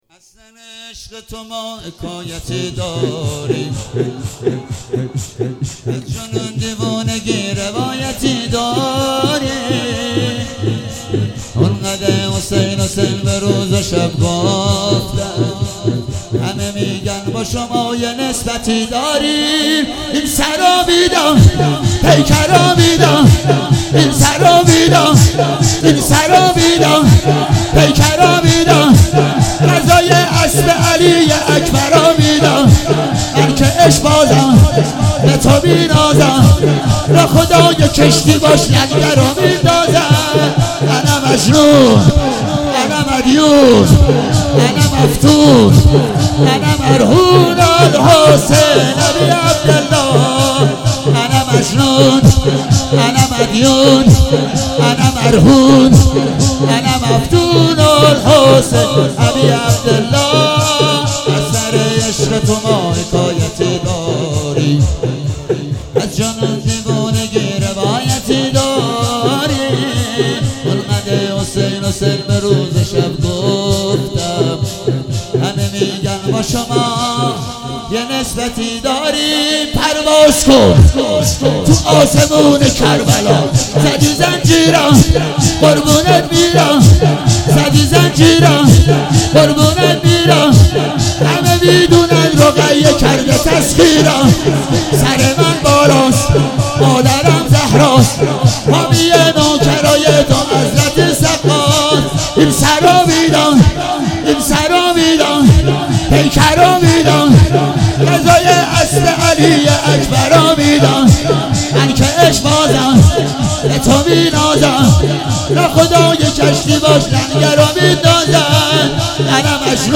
شب چهارم محرم 97 - شور - از سره عشقه تو ما